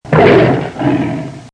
B_LION.mp3